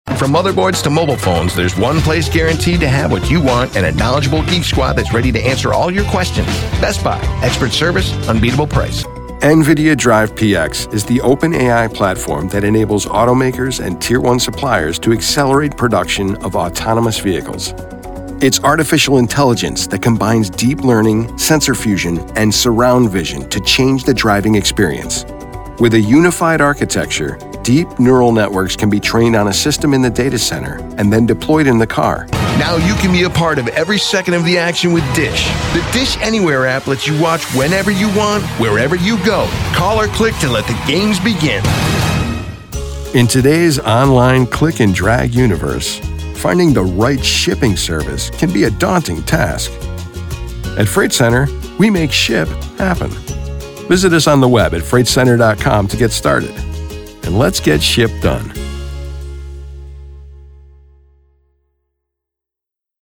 SOURCE-CONNECT Certified US MALE VOICOVER with HOME STUDIO
• BOOTH: Whisper Room, acoustically-treated
quirky
MIX9-quirky.mp3